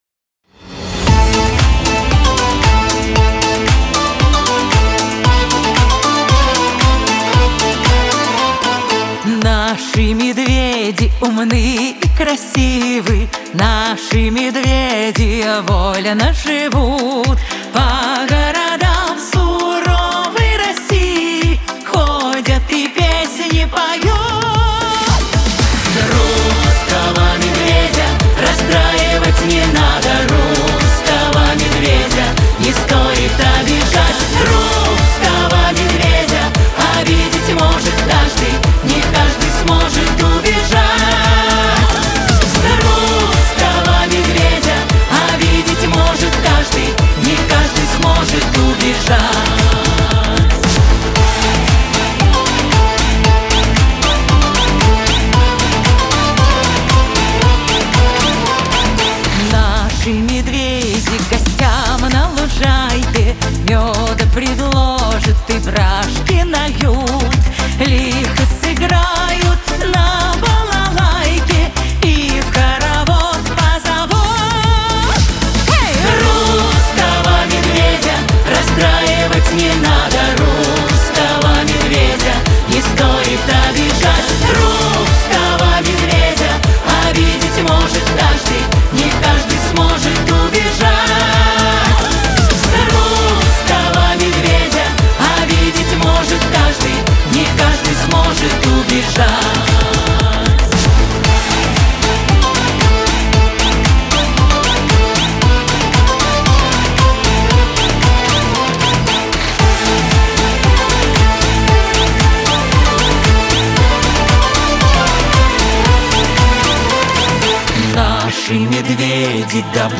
Лирика , дуэт
pop
эстрада